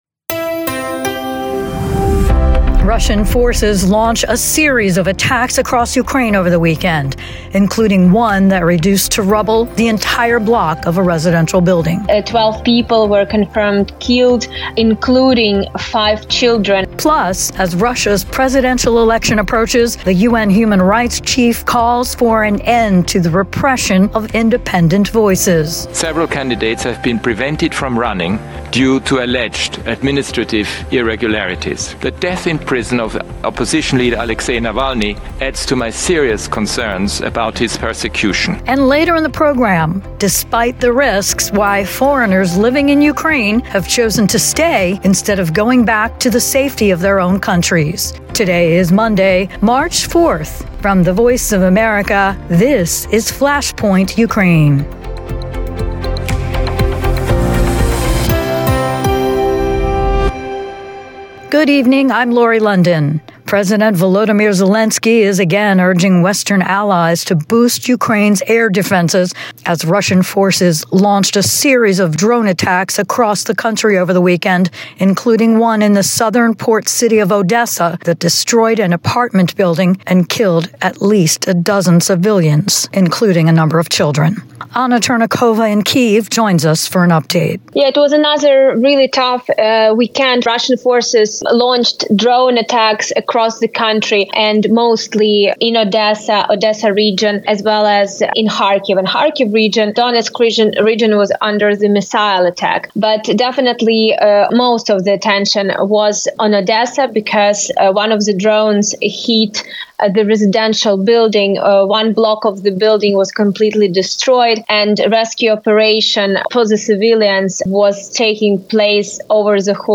reports from Kyiv